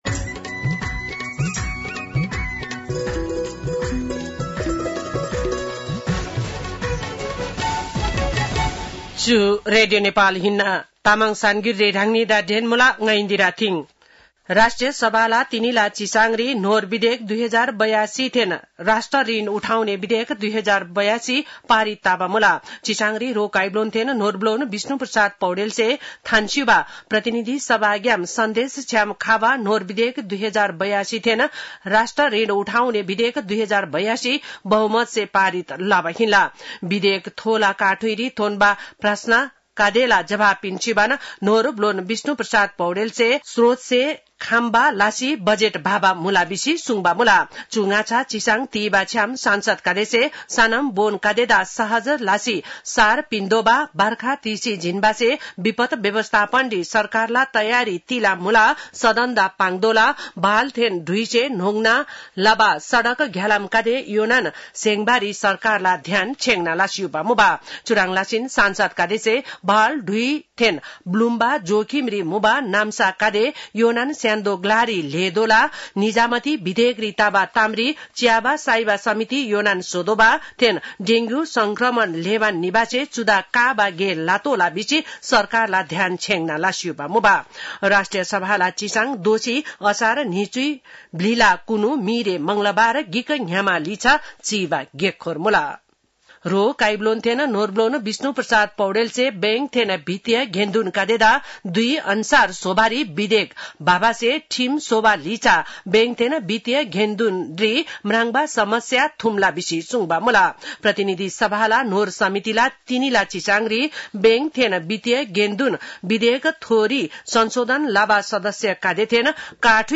तामाङ भाषाको समाचार : २० असार , २०८२